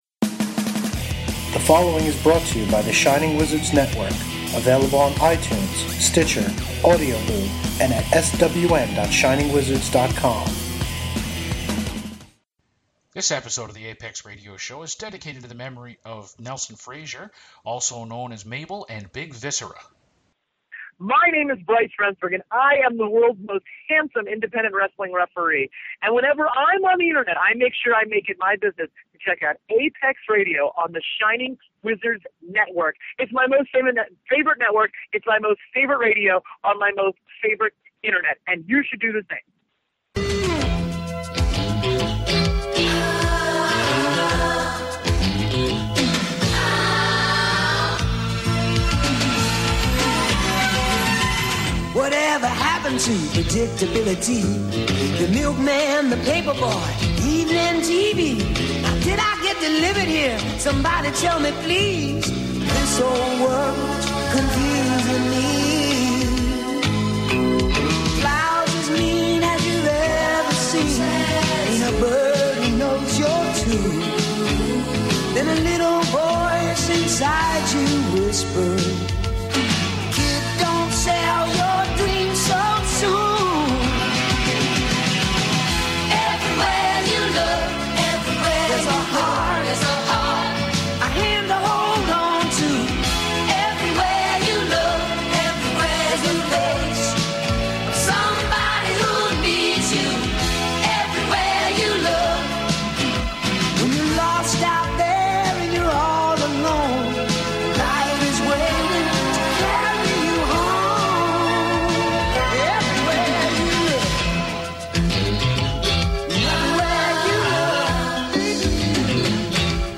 Don’tcha dare miss any of this fantastic and wildly entertaining interview, or APEX